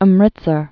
(əm-rĭtsər)